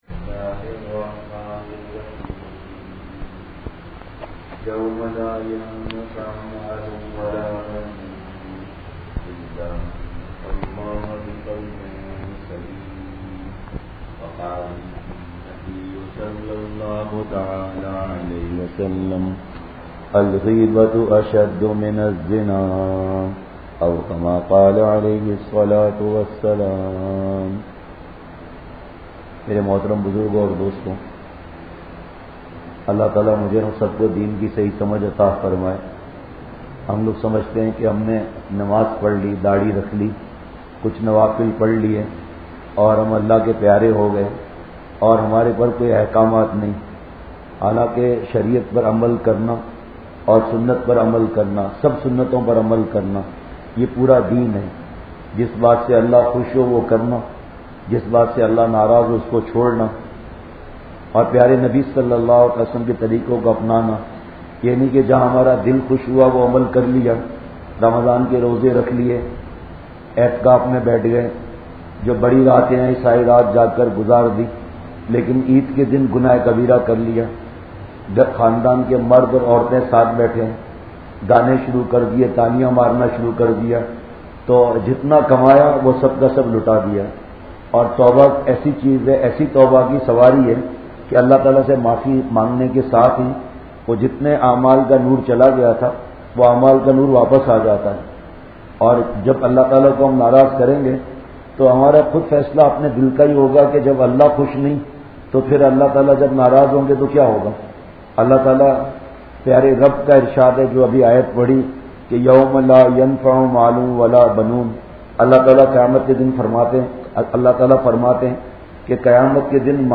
مجلس۳،اگست ۲۰۱۶ء:اولاد کو دین سکھائیں(حیدرآباد سفر بیان
Majlis 3 Aug 2016_Aolad Ko Deen Seekhaye_Jamia Masjid Bilal Kohsar HousingSociety Hyderabad Pakistan.mp3